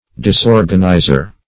Search Result for " disorganizer" : The Collaborative International Dictionary of English v.0.48: Disorganizer \Dis*or"gan*i`zer\, n. One who disorganizes or causes disorder and confusion.